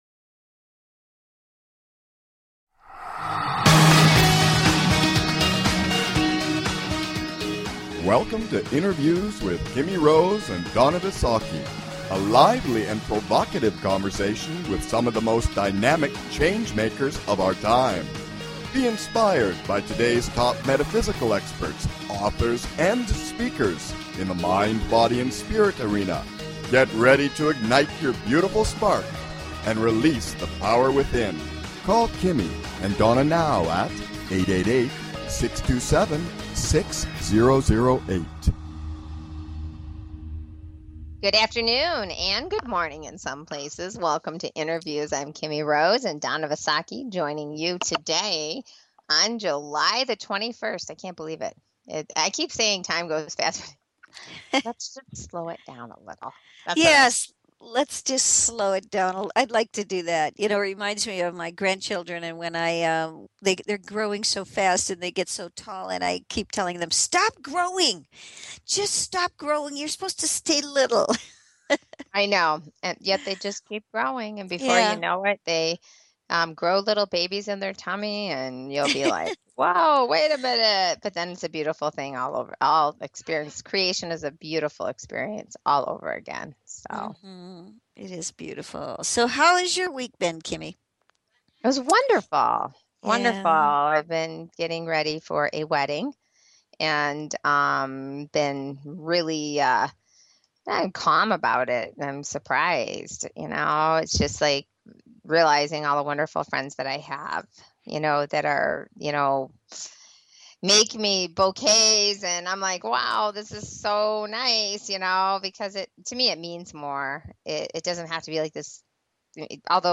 Log in or register to post comments Show Tags Adventure Travel Archive Category Earth & Space Health & Lifestyle Philosophy Spiritual Travel & Leisure InnerViews Please consider subscribing to this talk show.